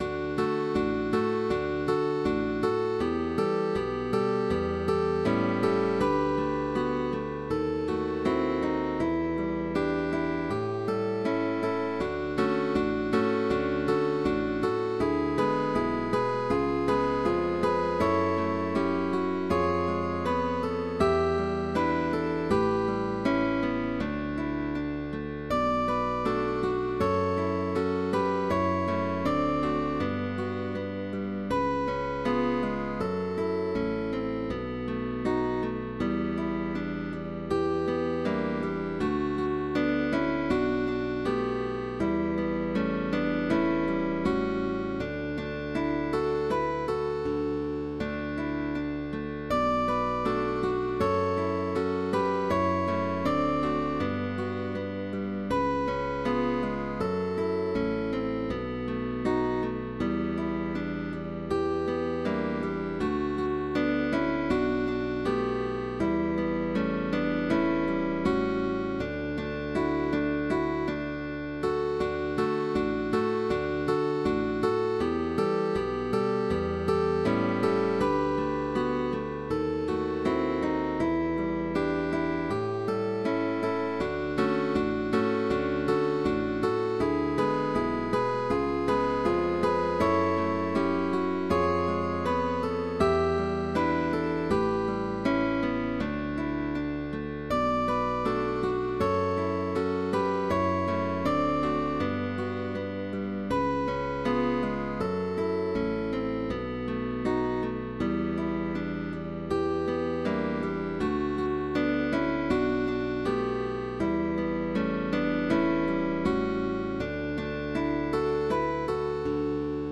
Guitar trio sheetmusic.
GUITAR TRIO
Change of position, chords, slurs, barres, arpeggios,...